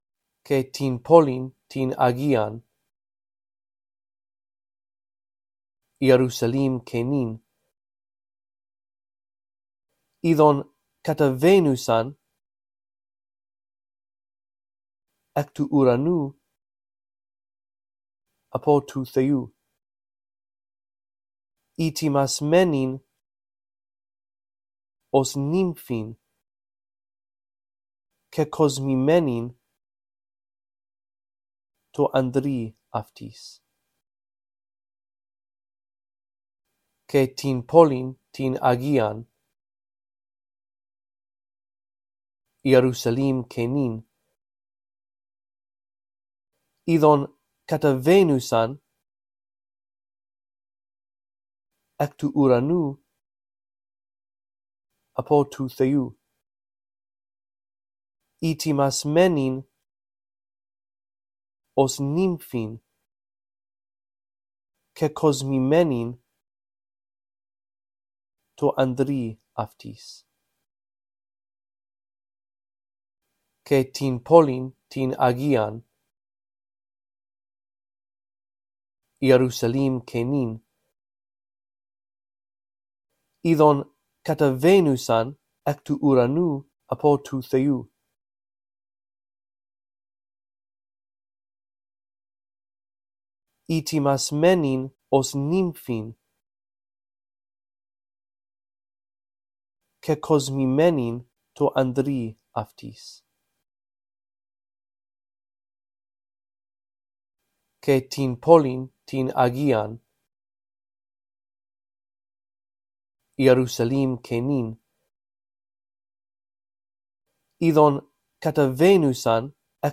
In this audio track, I read through verse 2 a phrase at a time, giving you time to repeat after me. After two run-throughs, the phrases that you are to repeat become longer.